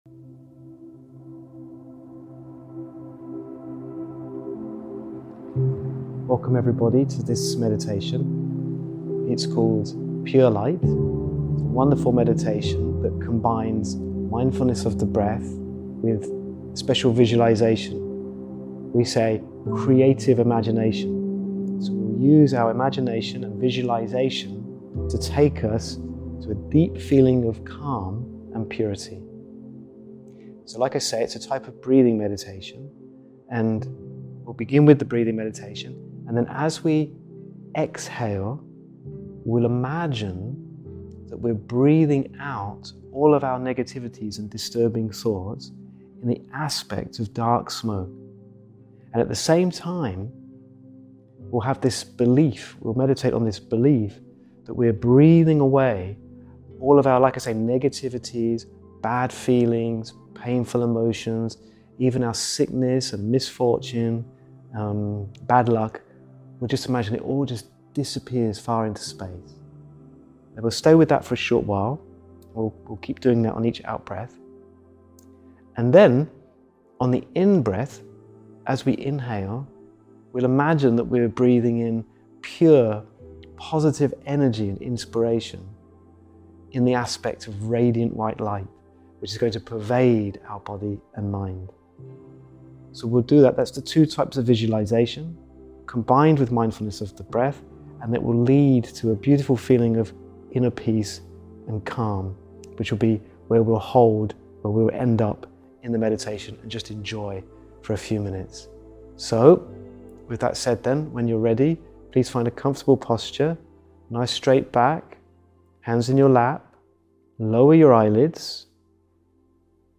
Meditation for Stress Relief